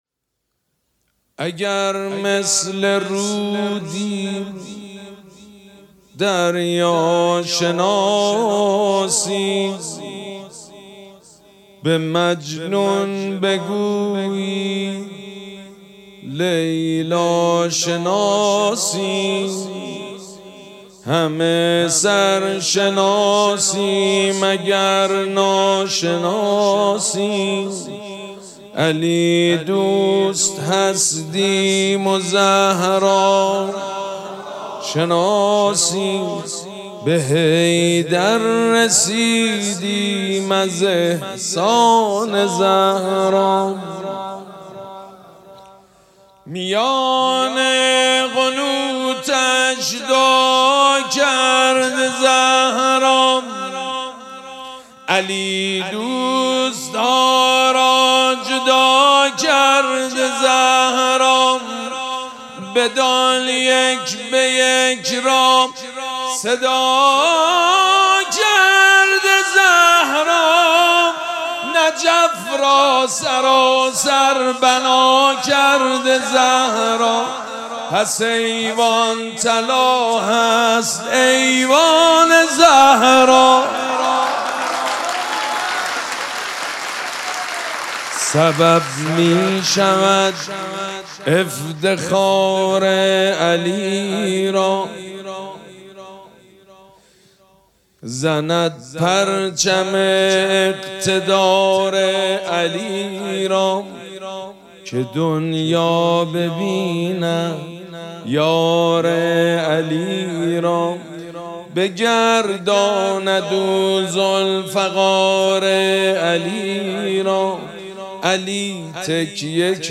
مدح احساسی زیبا